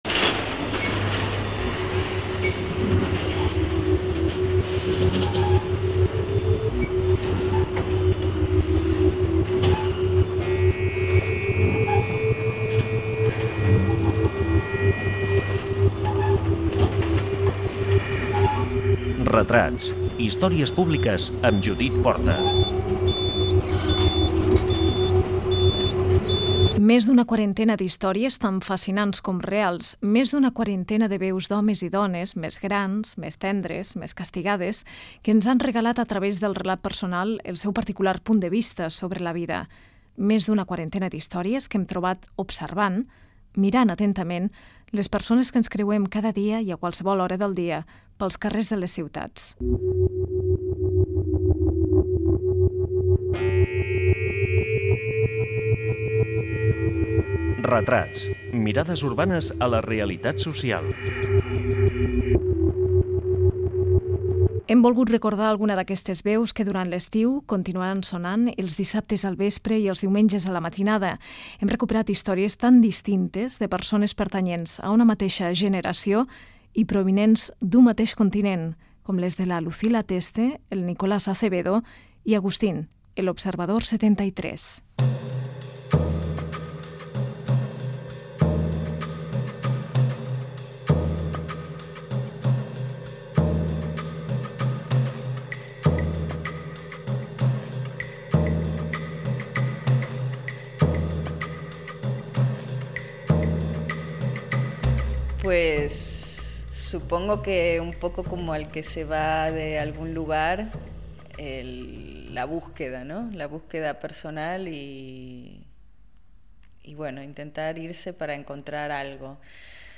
Careta del programa, presentació del programa que recopila mirades urbanes a la realitat social. Diversos testimonis sobre aquesta qüestió de persones procedents de llocs diversos
Divulgació